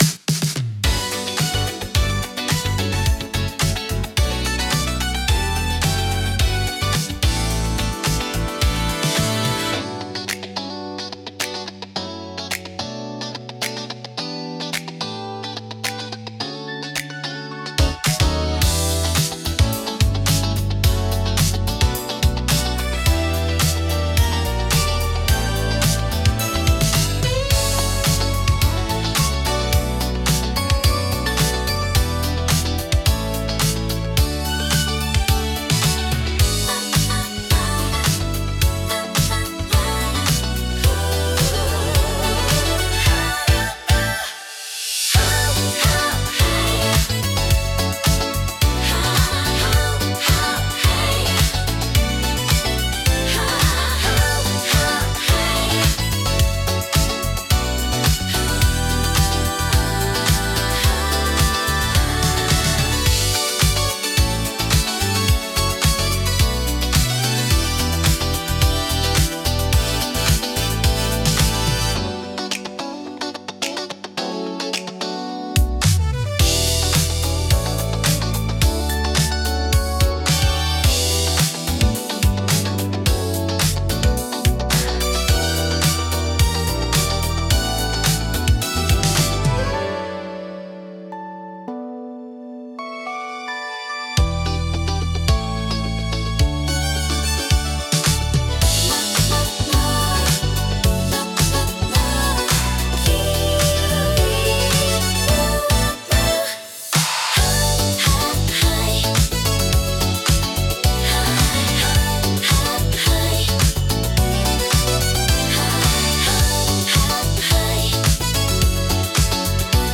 シティポップは、1970～80年代の日本で生まれたポップスの一ジャンルで、都会的で洗練されたサウンドが特徴です。
聴く人にノスタルジックかつモダンな気分を届けるジャンルです。